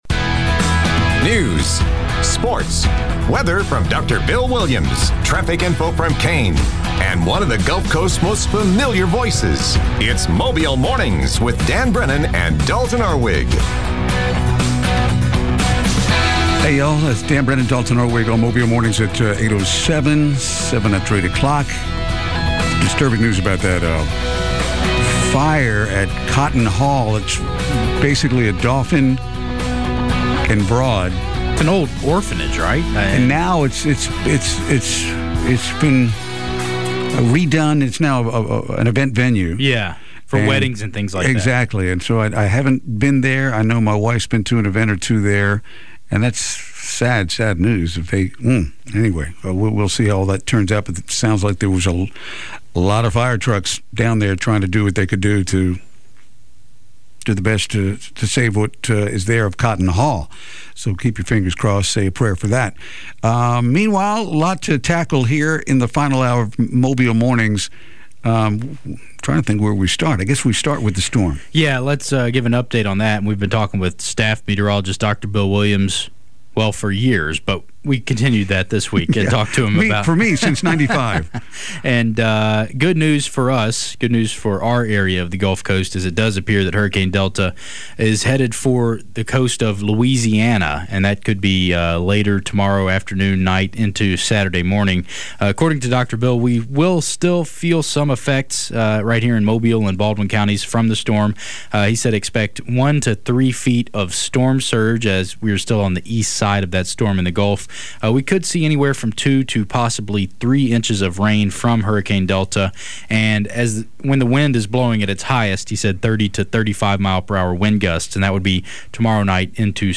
report on local news and sports